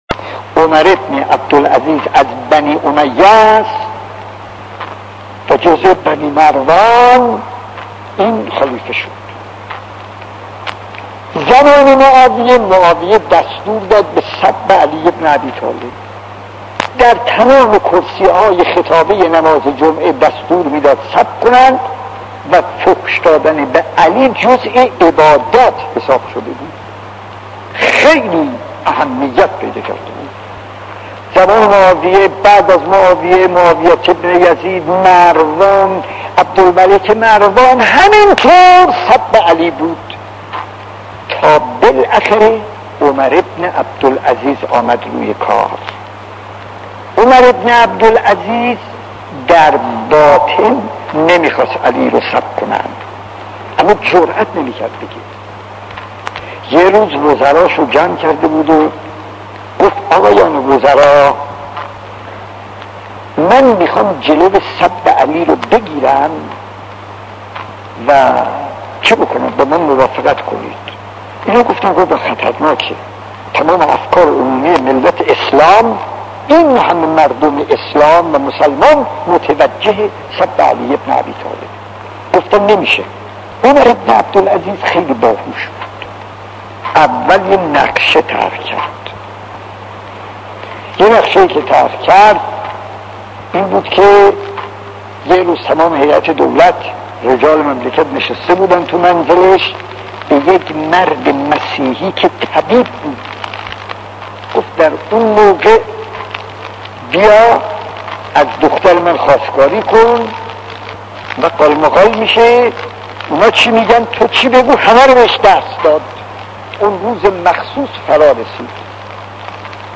داستان 9 : عمر ابن عبدالعزیز و سب امام علی خطیب: استاد فلسفی مدت زمان: 00:07:47